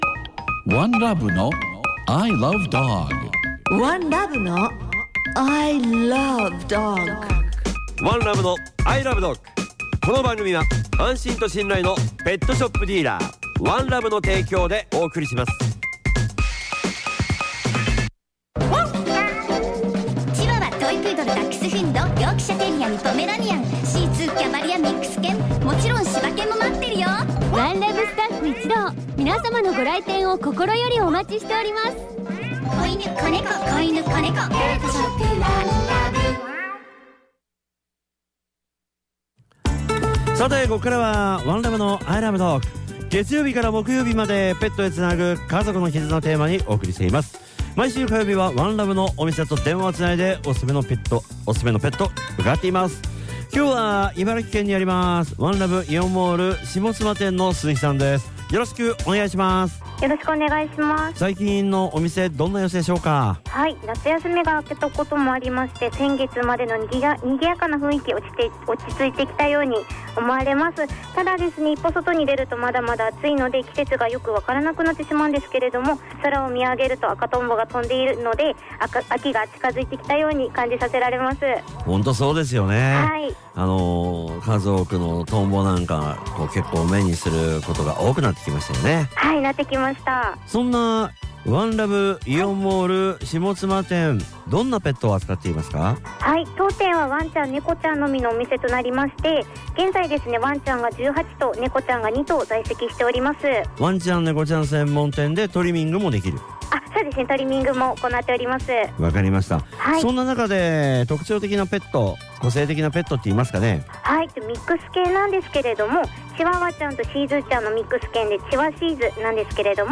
月曜は、街角突撃インタビューが聞けるワン！